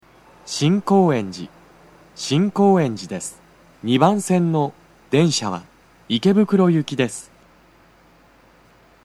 スピーカー種類 BOSE天井型
🎵到着放送
足元注意喚起放送の付帯は無く、フルの難易度は普通です
2番線 池袋方面 到着放送 【男声